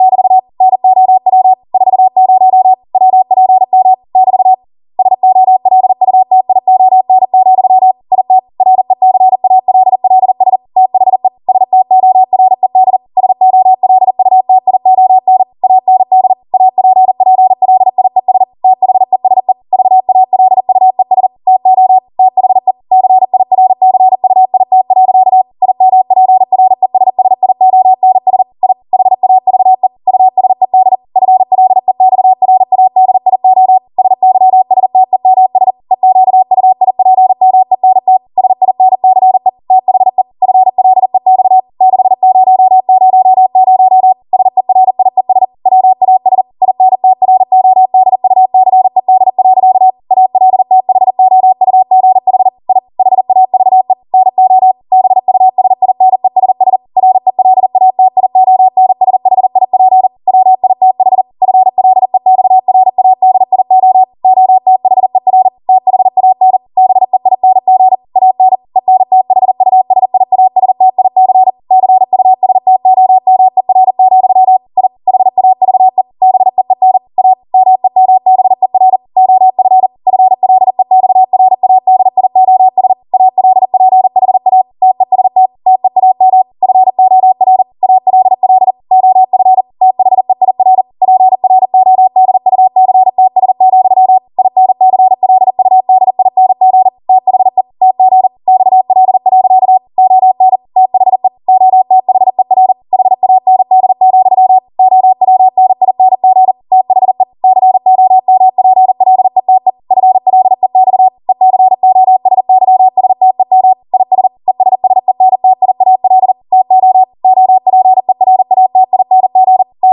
40 WPM Code Archive